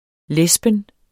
Udtale [ ˈlεsbən ]